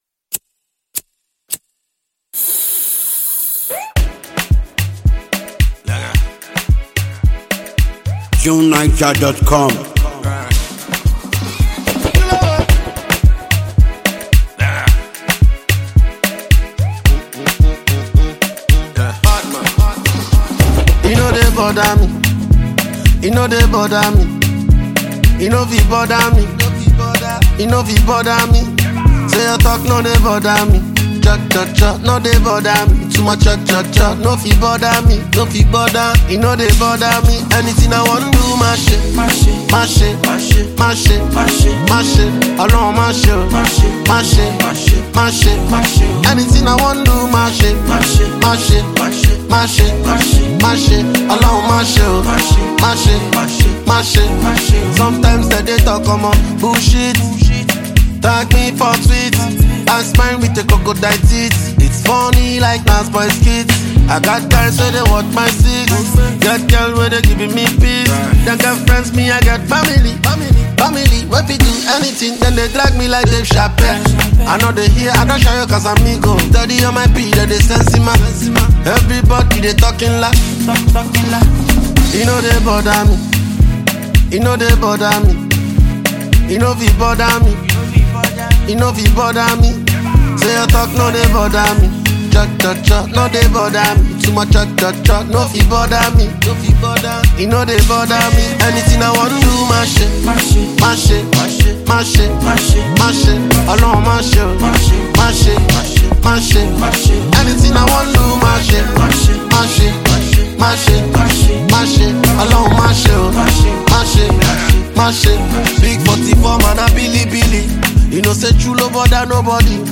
upbeat song